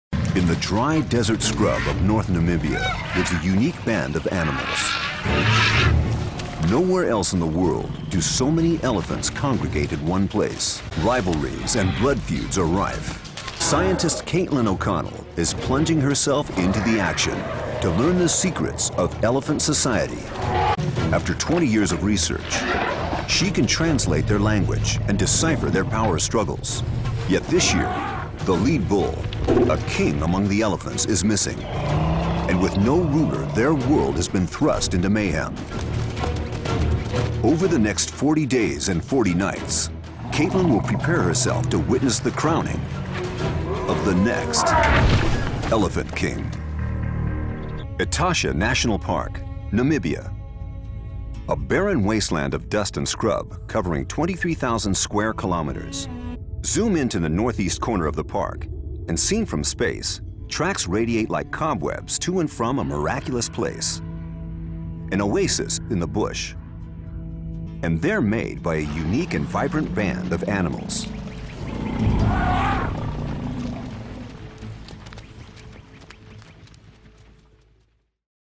Wildlife Narration